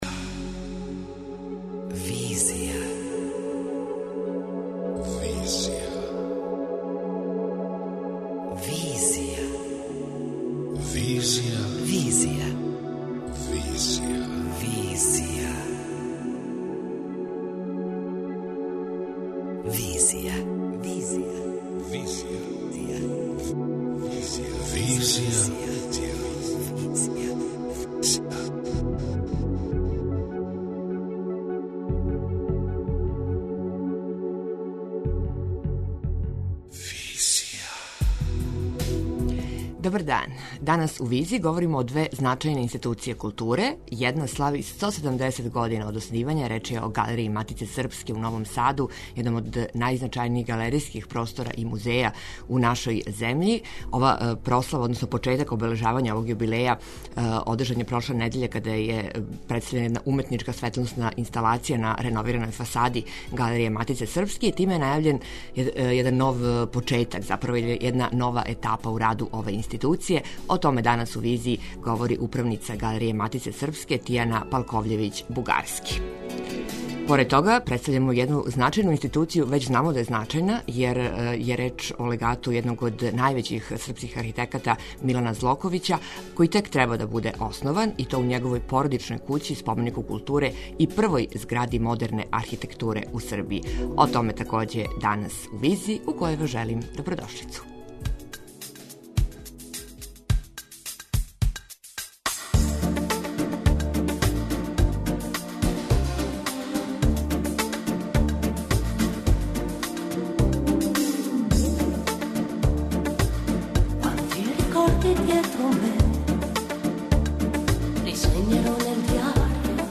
преузми : 27.62 MB Визија Autor: Београд 202 Социо-културолошки магазин, који прати савремене друштвене феномене.